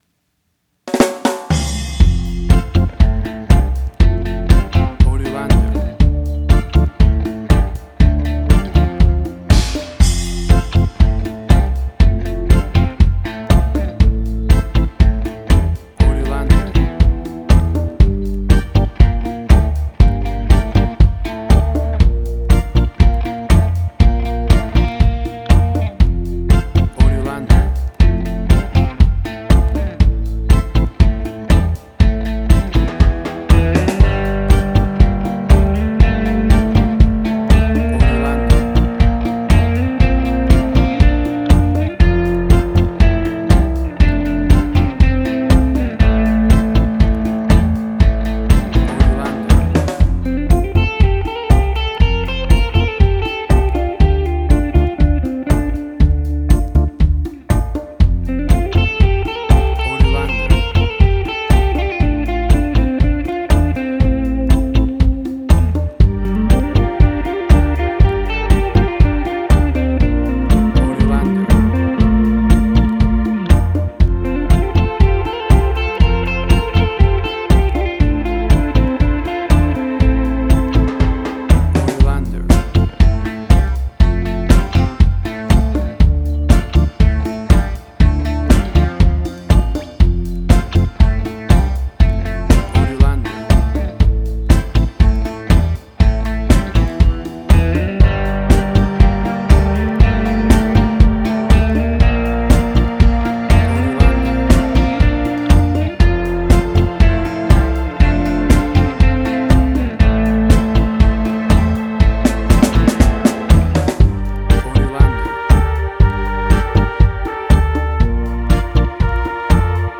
Reggae caribbean Dub Roots
Tempo (BPM): 120